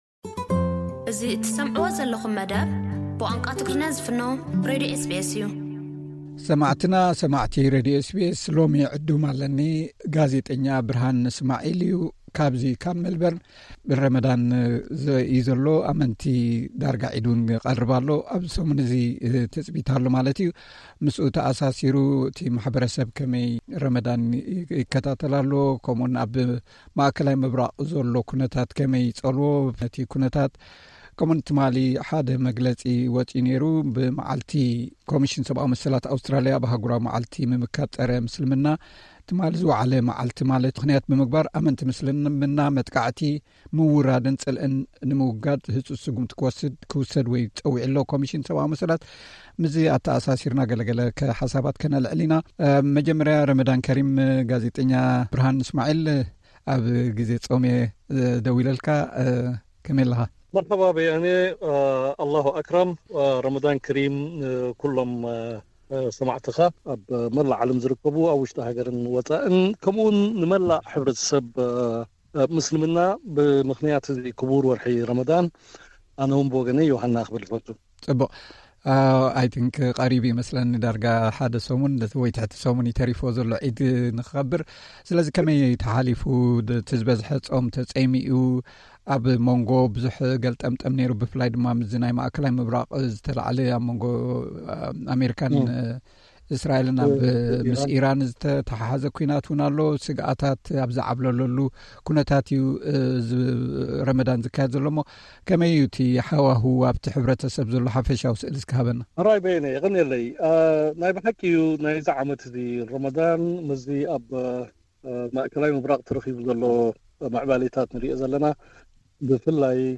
ጻኒሒት